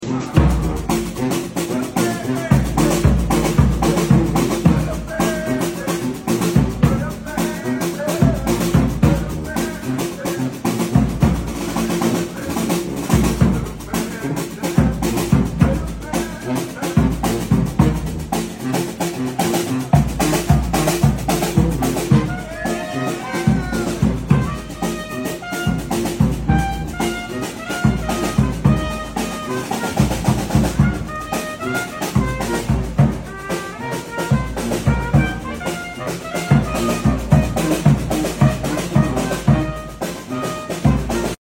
Idk who’s idea it was to have a brass band stand alongside the Dos Equis sample cart but I’m sure all of the stores appreciated the entertaining.